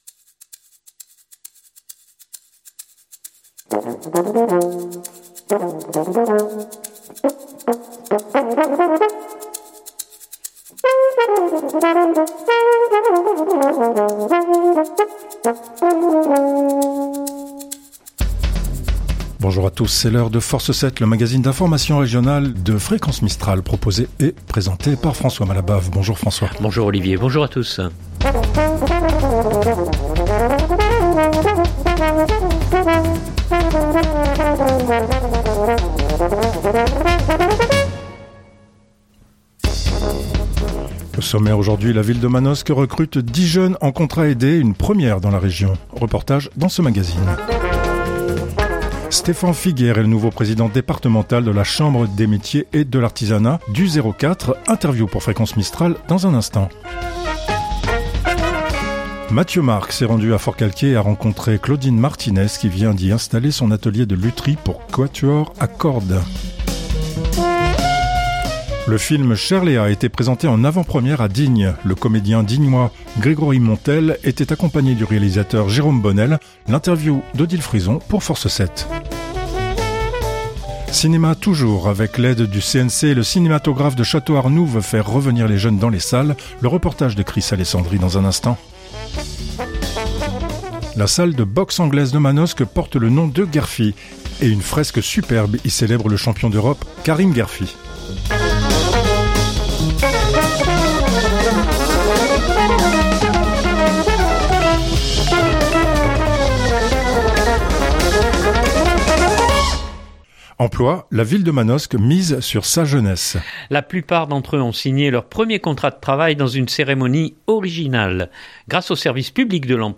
Interview pour Fréquence Mistral.